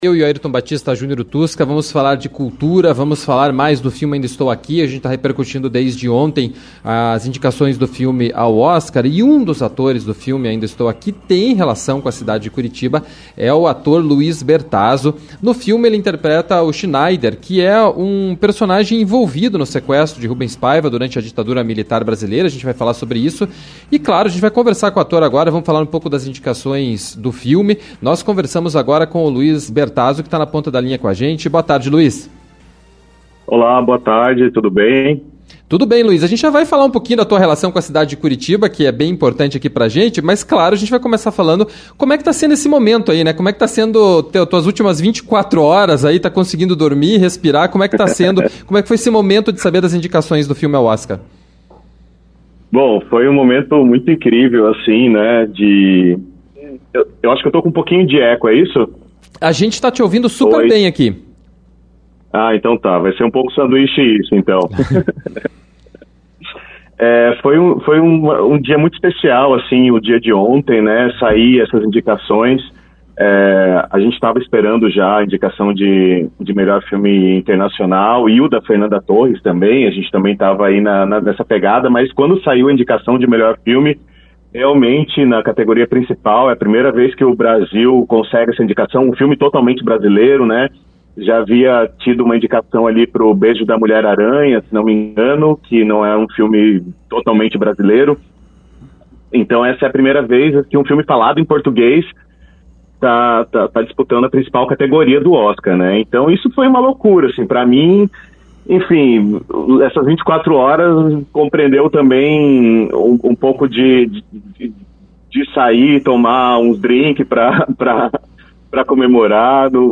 Entrevista-2401.mp3